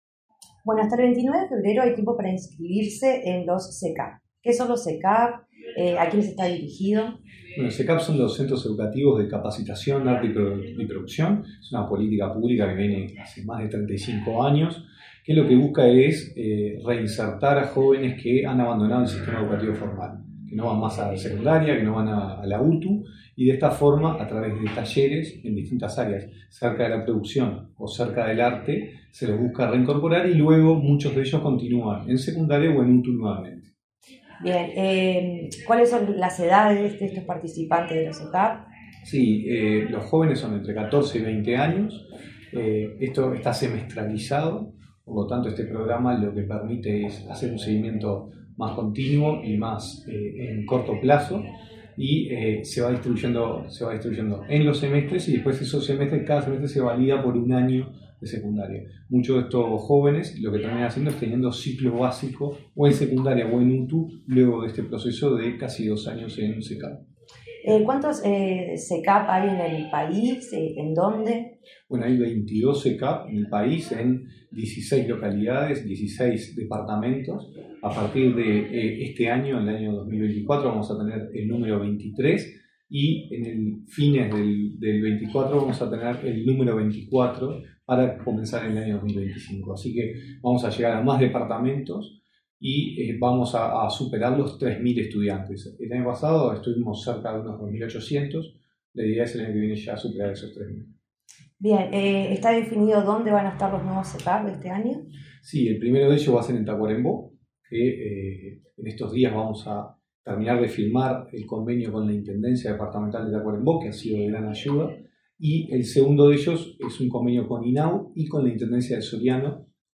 Entrevista a director nacional de Educación, Gonzalo Baroni